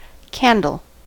candle: Wikimedia Commons US English Pronunciations
En-us-candle.WAV